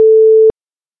phone-outgoing-busy-fr.oga